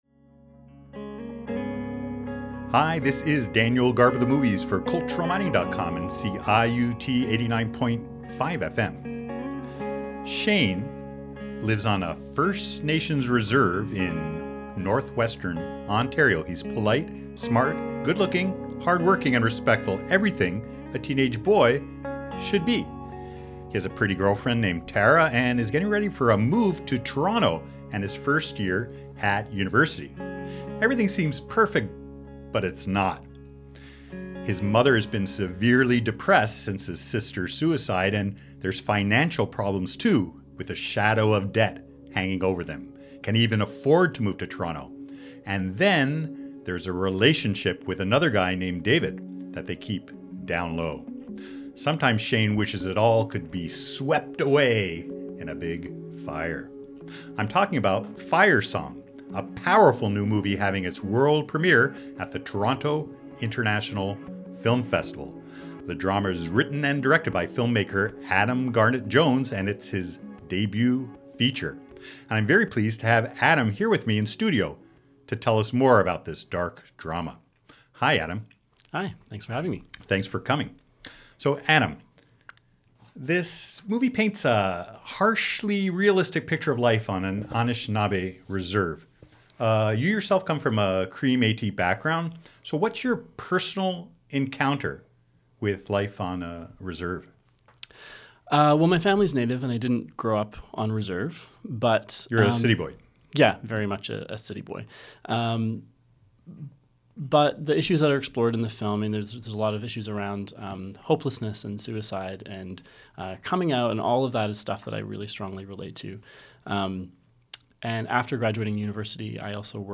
I spoke to him at CIUT.